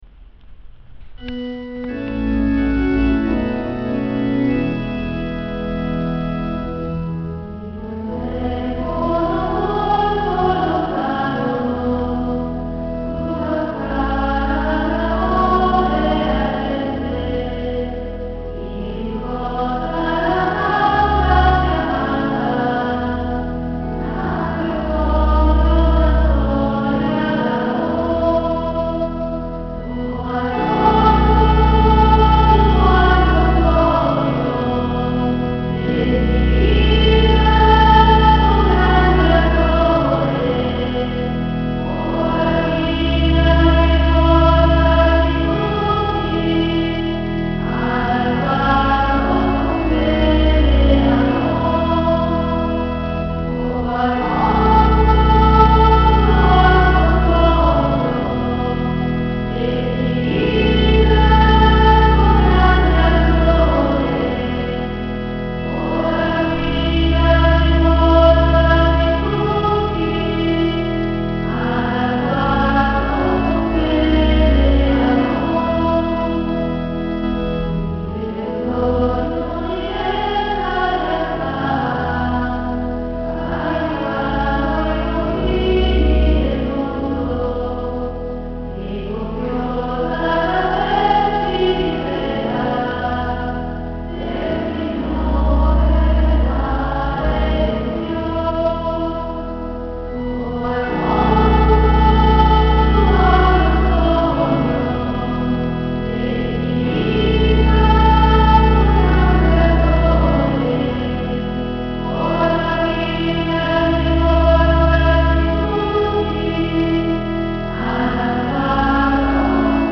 L’intero fascicolo è scaricabile in formato pdf; i testi dei singoli canti in formato *.txt. Anche le registrazioni in mp3 hanno valore di demo, cioè un aiuto ad imparare il canto. Le registrazioni sono state fatte qualche anno fa dalle Monache Redentoriste di Scala (purtroppo, non di tutti i canti).